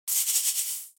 دانلود آهنگ بیابان 14 از افکت صوتی طبیعت و محیط
دانلود صدای بیابان 14 از ساعد نیوز با لینک مستقیم و کیفیت بالا
جلوه های صوتی